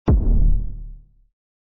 SOPHIE_kick_wet_07